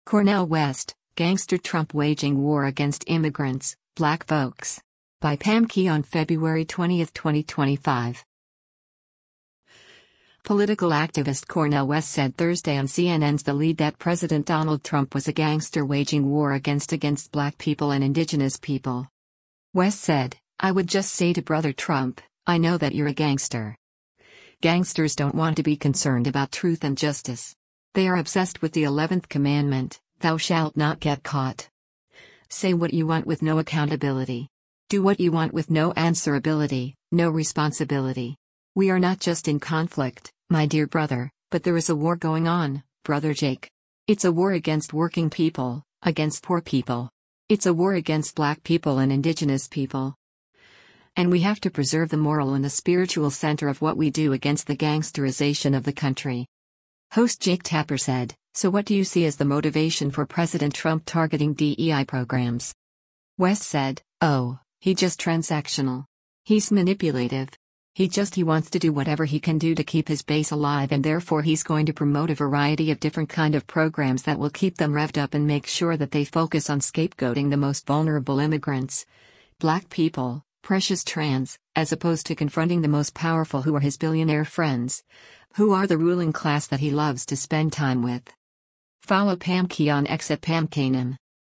Political activist Cornel West said Thursday on CNN’s “The Lead” that President Donald Trump was a “gangster” waging “war” against “against black people and indigenous people.”